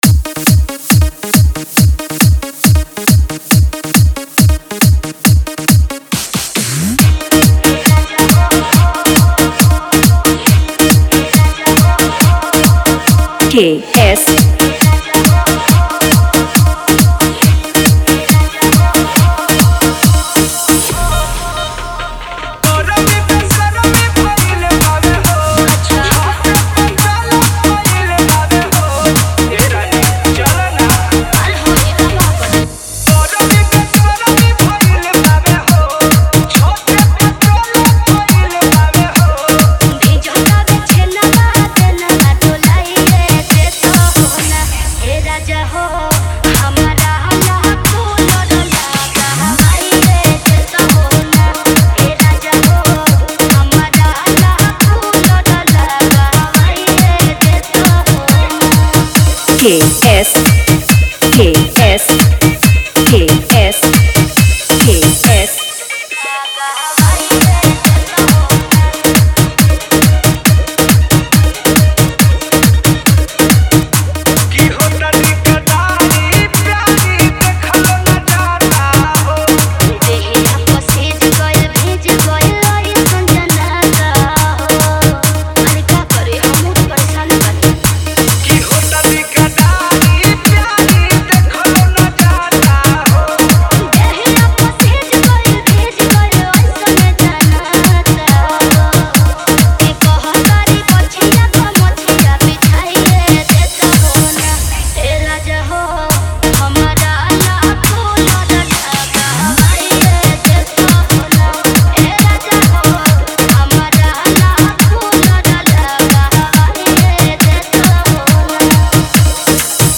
Party Song Dj Remix